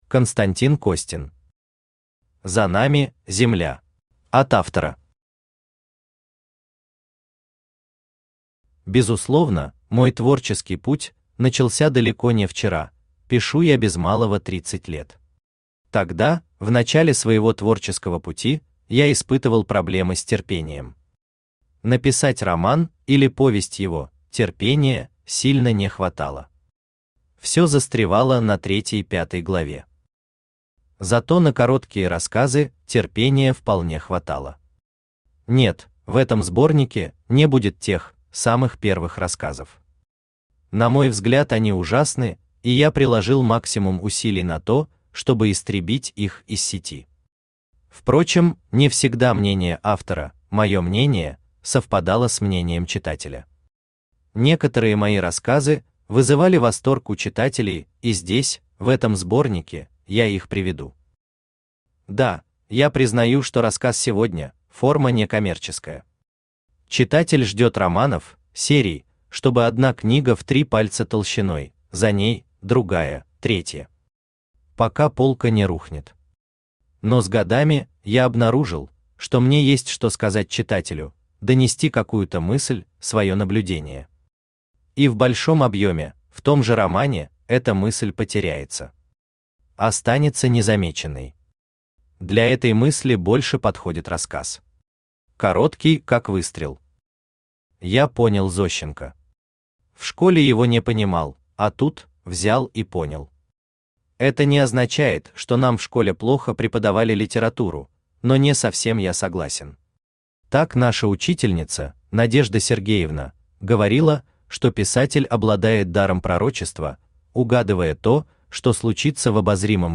Аудиокнига За нами – Земля!
Автор Константин Александрович Костин Читает аудиокнигу Авточтец ЛитРес.